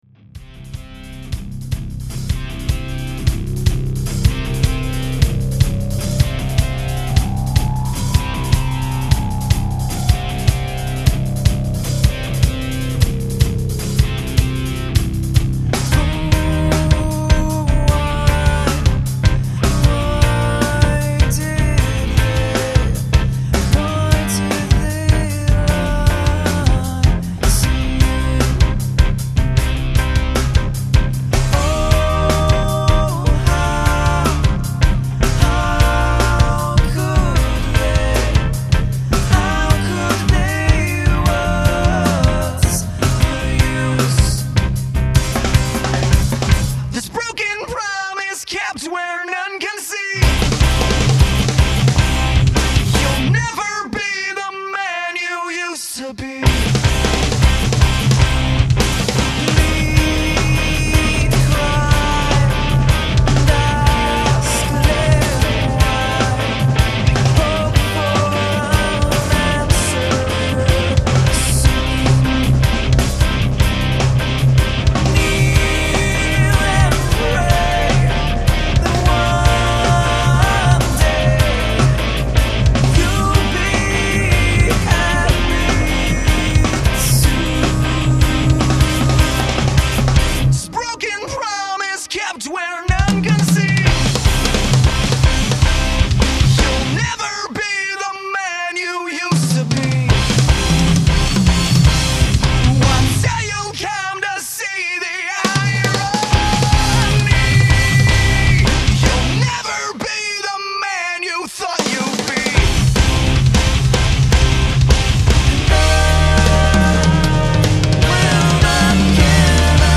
Cymbals are assorted Paiste Signature and 2002 models